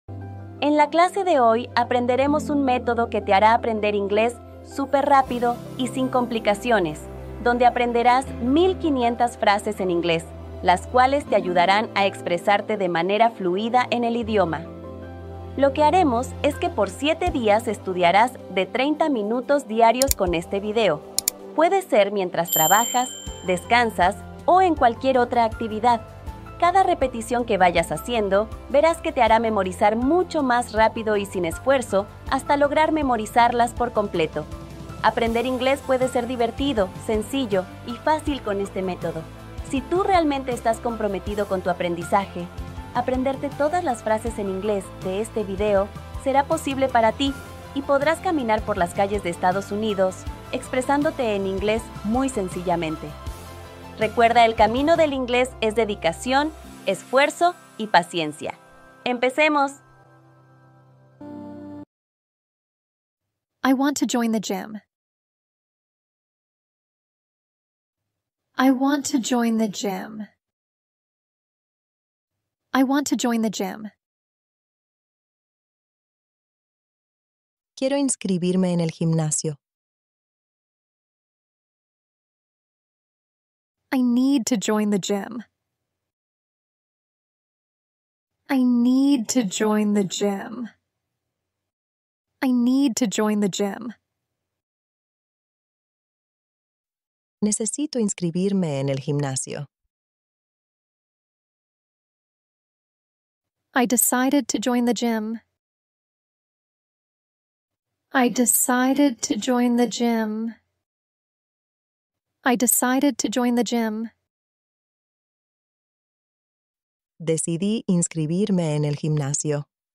Relájate bajo la lluvia ☔ y aprende inglés | Listening + vocabulario